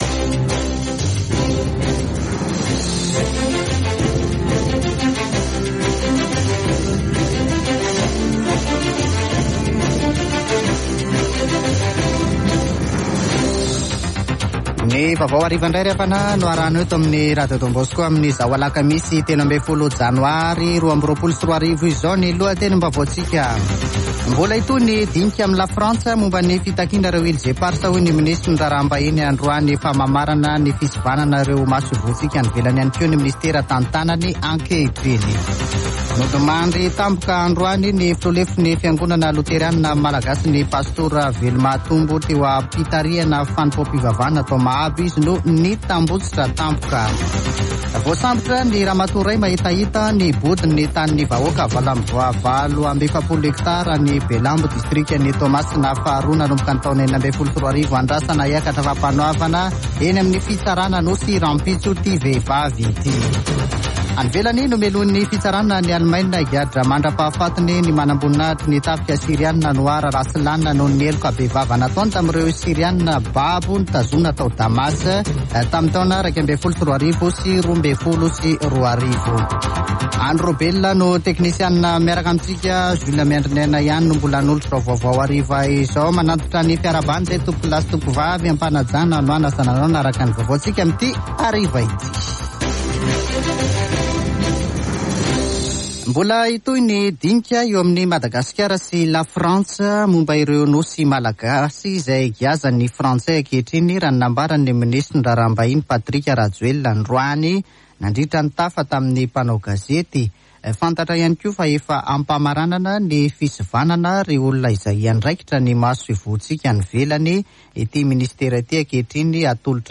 [Vaovao hariva] Alakamisy 13 janoary 2022